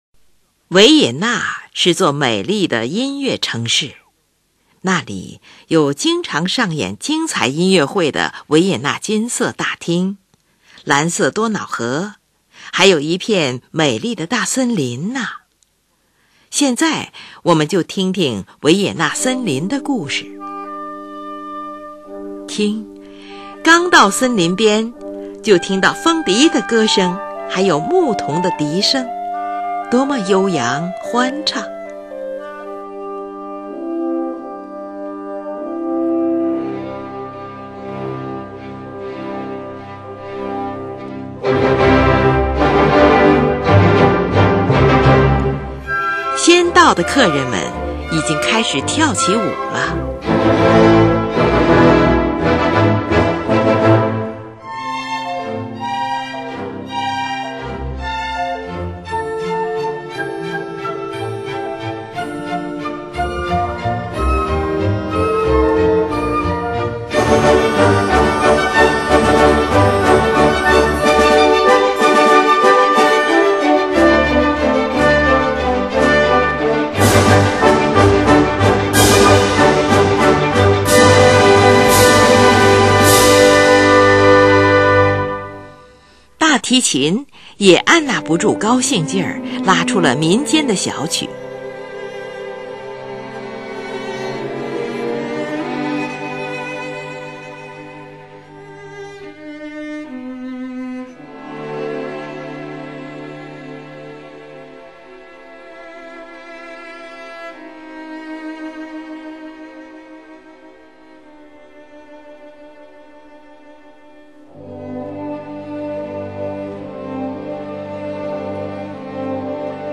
第一圆舞曲为F大调，描绘出了森林清晨的美景，即人们轻歌曼舞的场面。 第二圆舞曲为降B大调，由大提琴呈现出来。